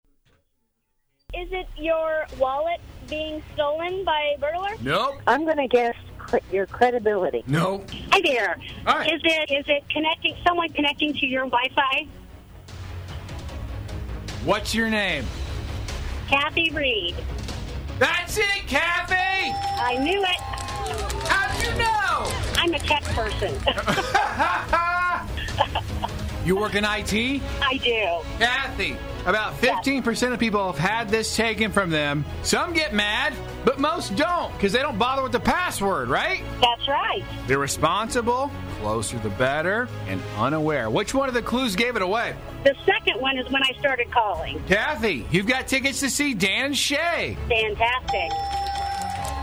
Listen close and you can hear the ice cream I struggled to keep in my mouth!